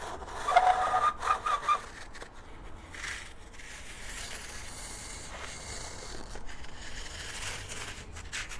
挠痒痒
描述：划伤。
Tag: 恐怖 搔抓